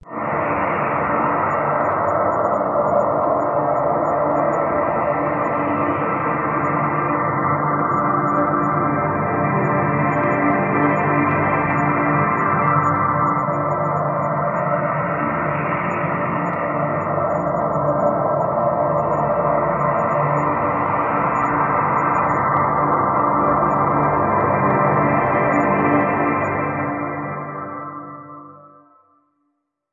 描述：TaDa sting composed of trumpets, trombones, french horns, trumpet section in Garageband.
标签： reveal fanfare brass win
声道立体声